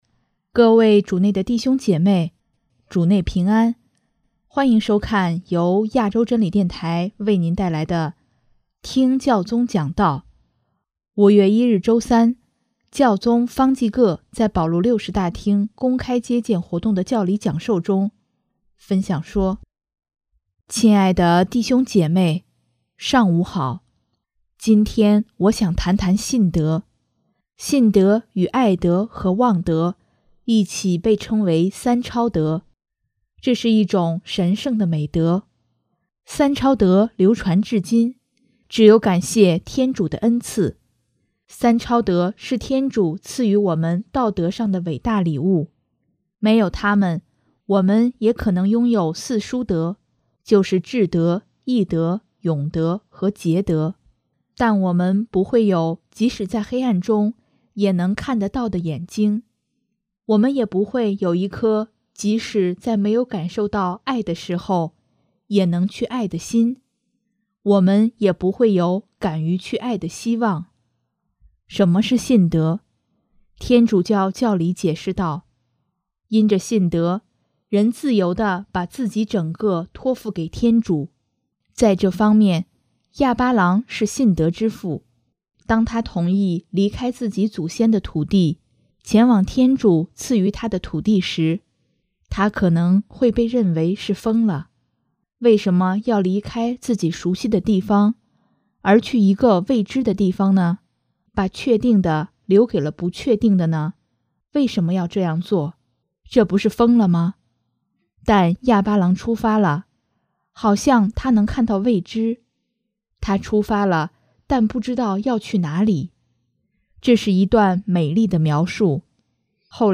5月1日周三，教宗方济各在保禄六世大厅公开接见活动的教理讲授中，分享说：